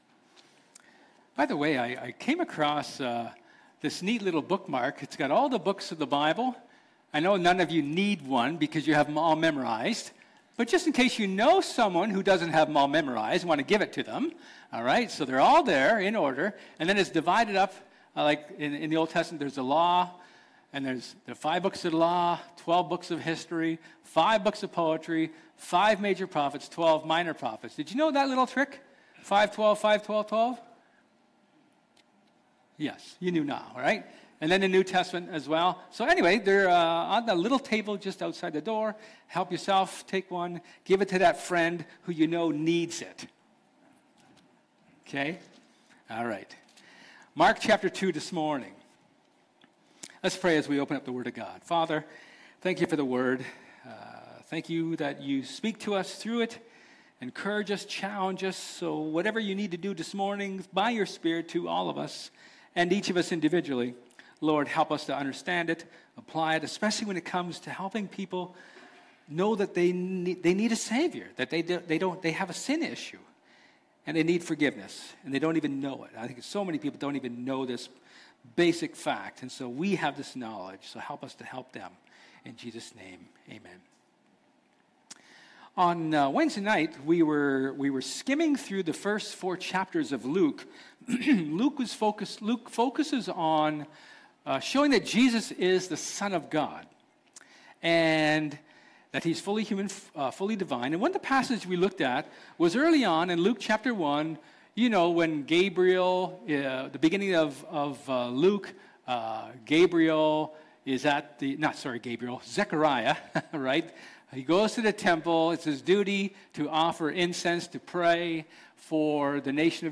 John 4:27-42 Service Type: Sermon